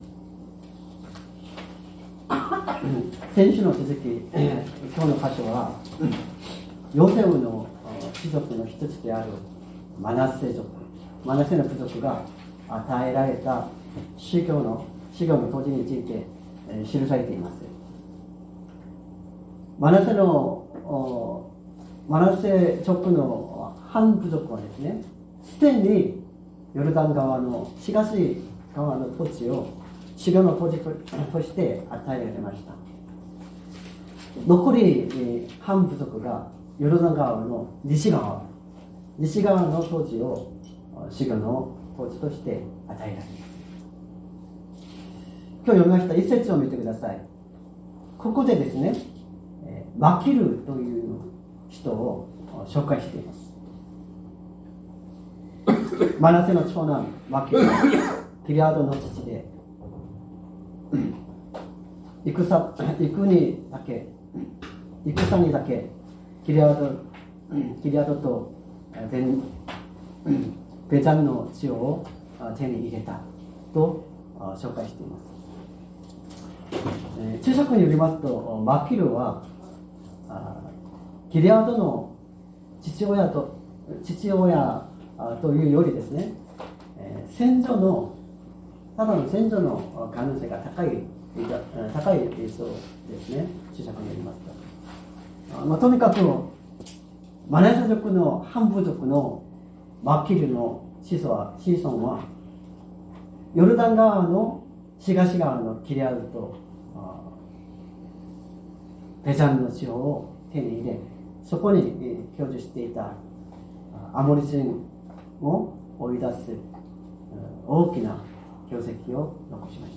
善通寺教会。説教アーカイブ 2025年02月02日朝の礼拝「自分のものにするがよい」
音声ファイル 礼拝説教を録音した音声ファイルを公開しています。